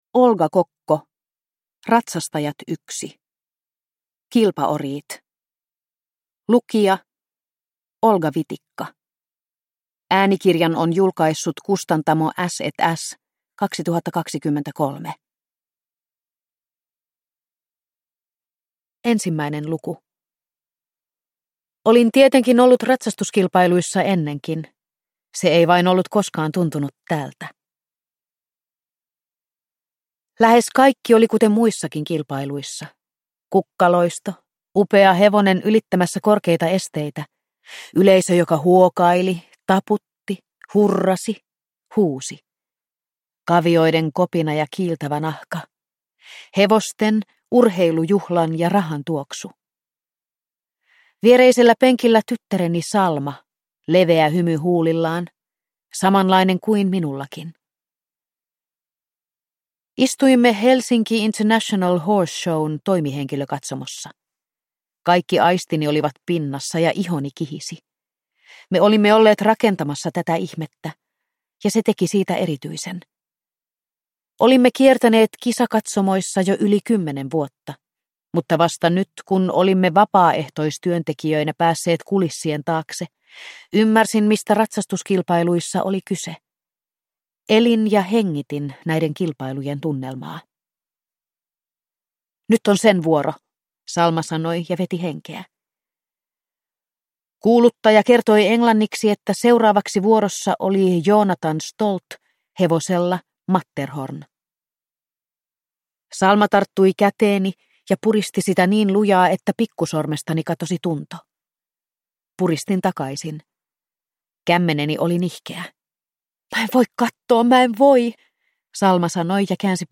Kilpaoriit (ljudbok) av Olga Kokko